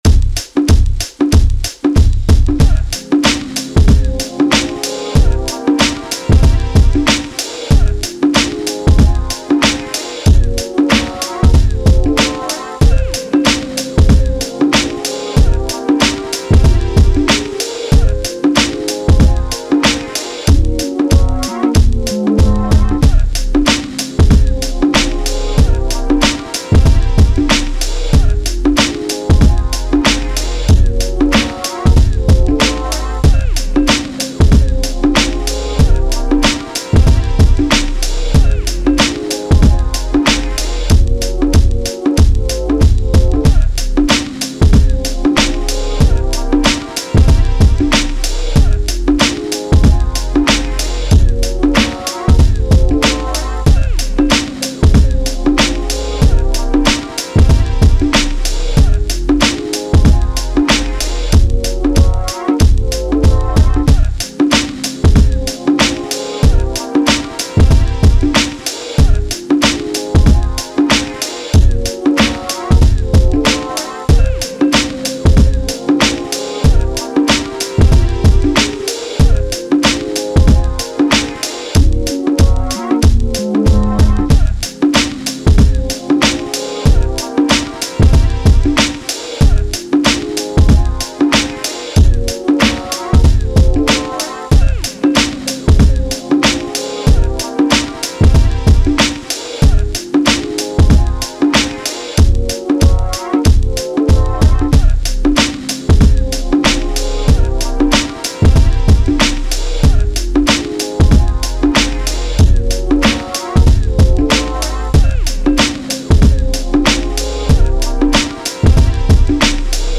R&B, 90s
C Minor